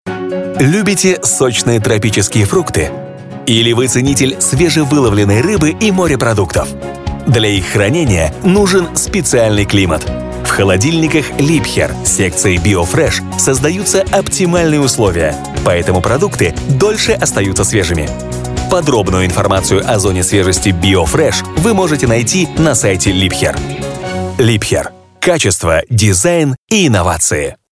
Разноплановый диктор.
Тракт: AKG414, Rode NTK -> WarmerSound MC41, dbx376 -> TC Impact Twin